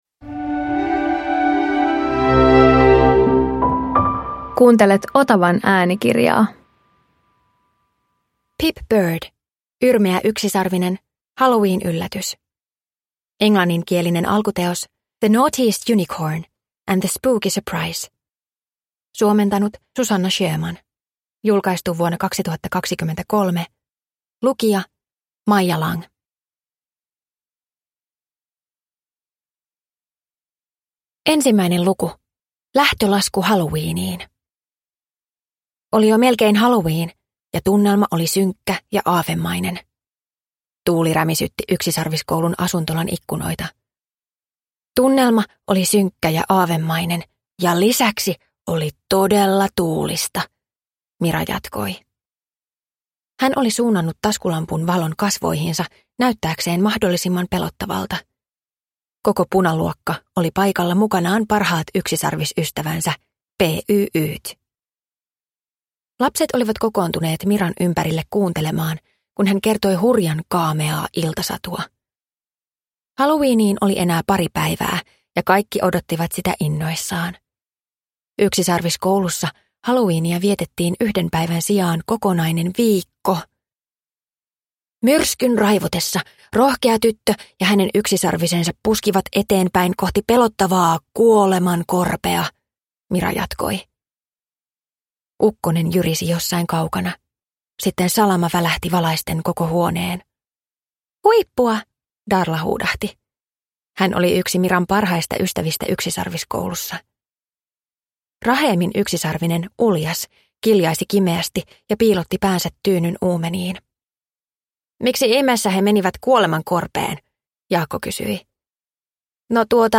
Yrmeä yksisarvinen - Halloweenyllätys – Ljudbok – Laddas ner